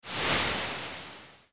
overheat.mp3